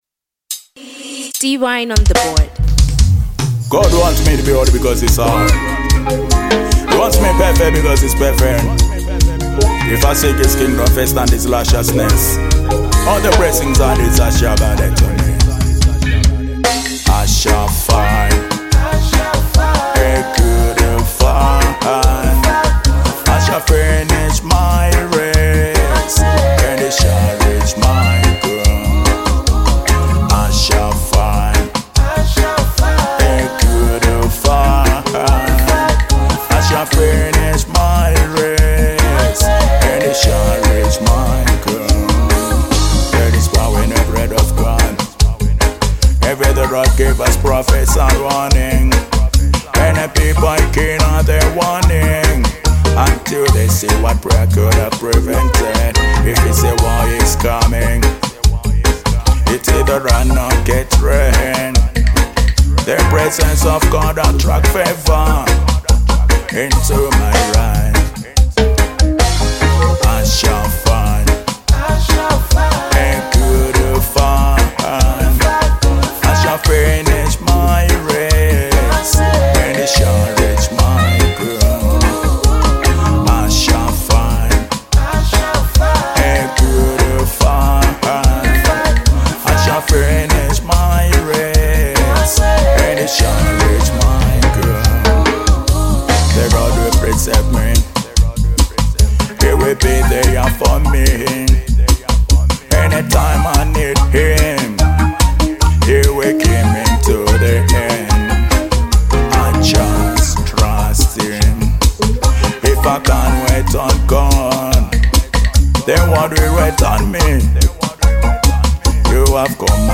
Genre : Reggae